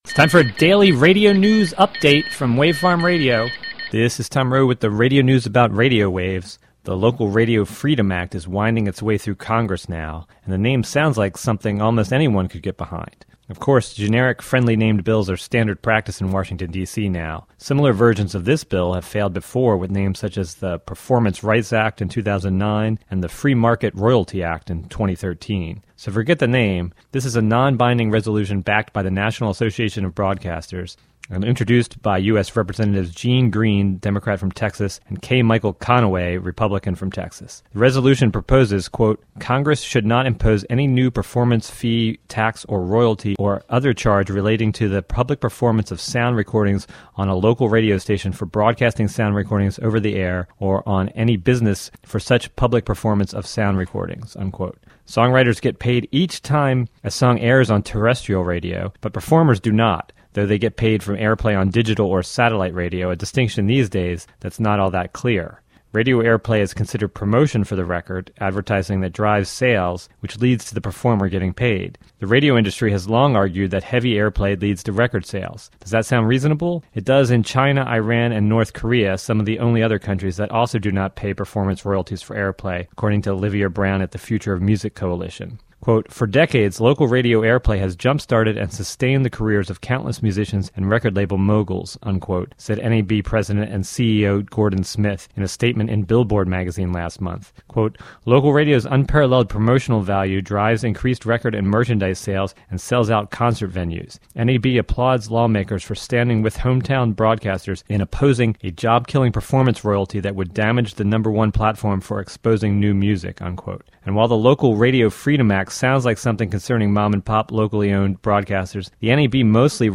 News report about a bill in Congress about performance royalities for radio stations.